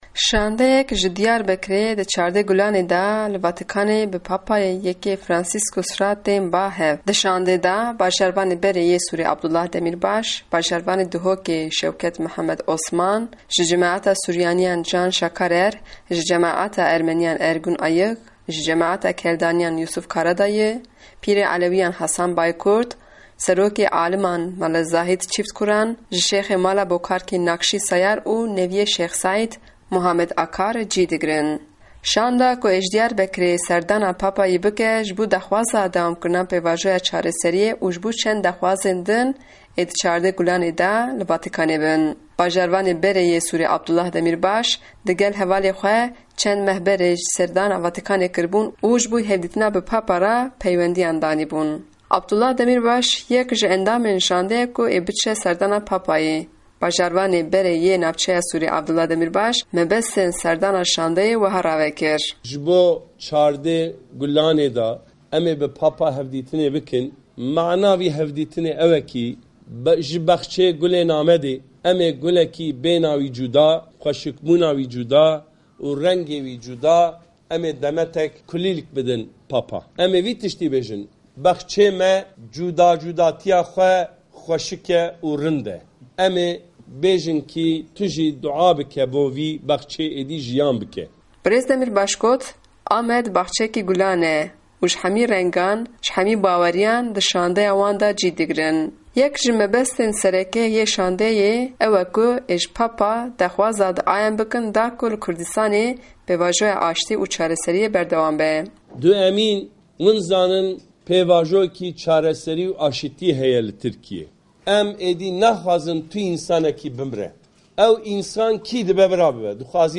Raporta Diyarbekirê